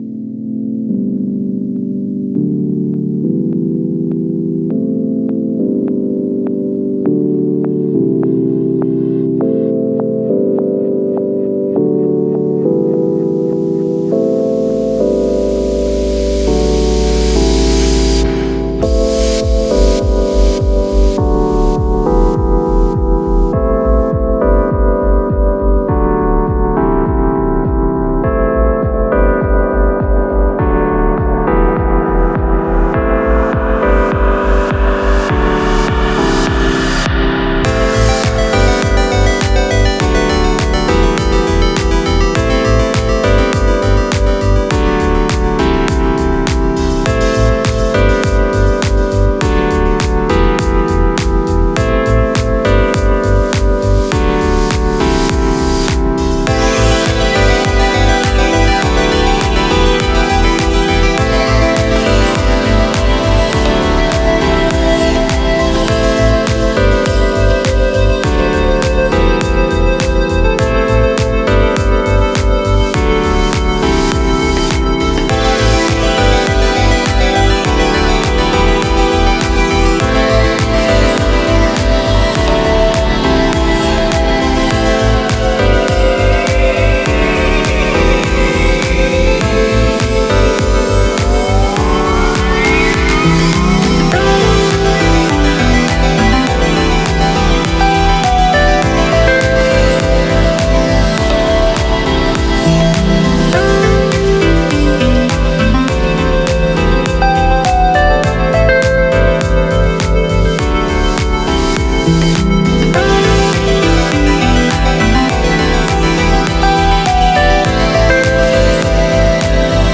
• Music has an ending (Doesn't loop)